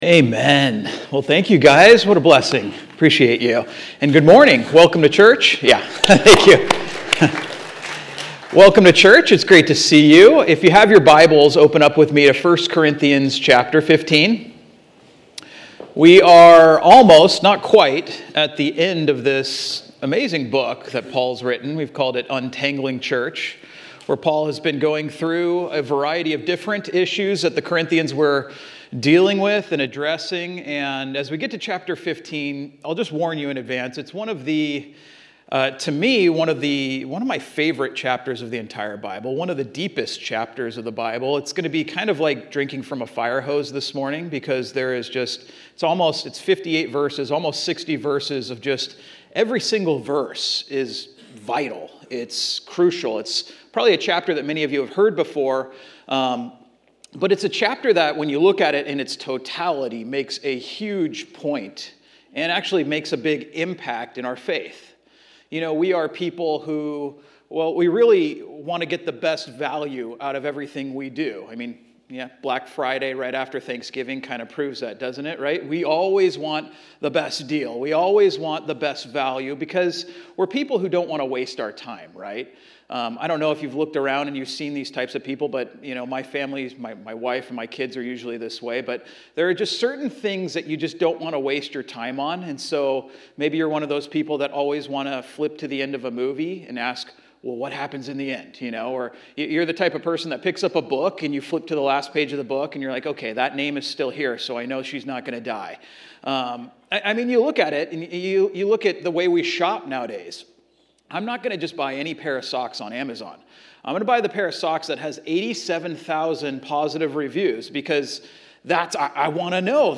Catch up with recent and past messages